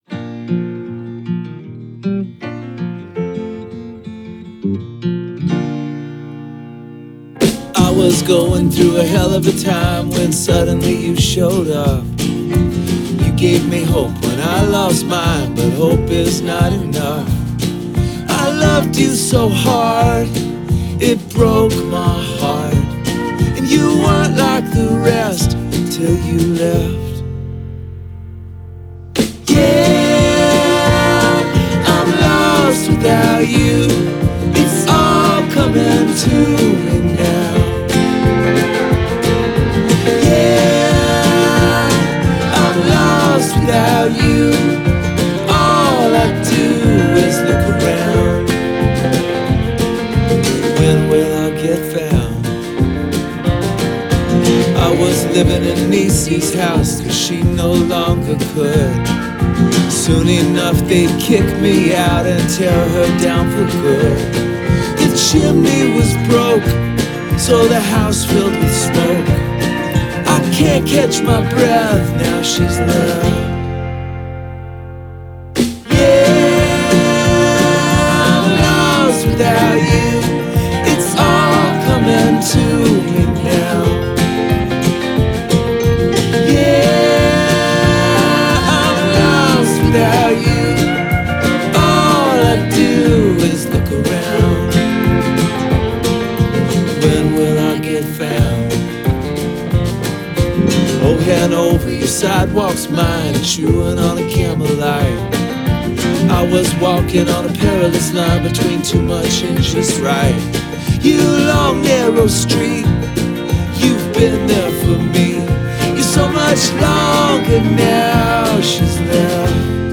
has a more traditional country feel